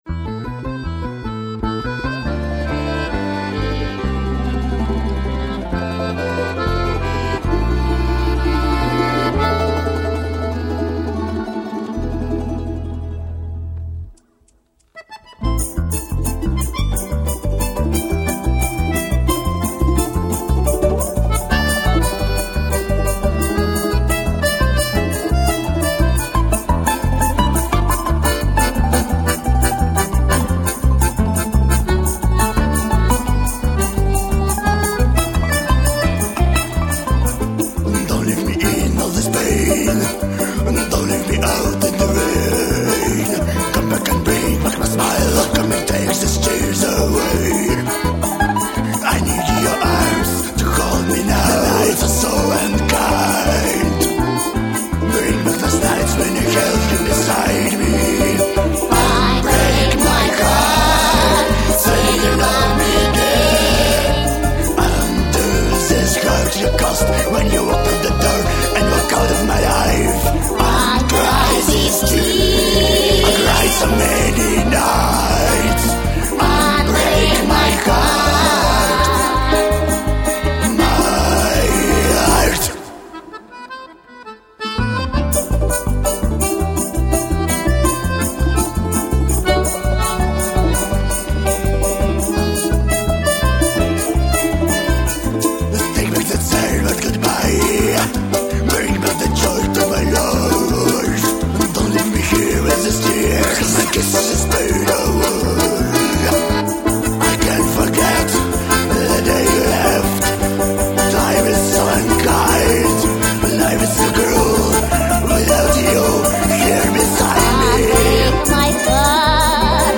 русские народные голоса поют припев на английском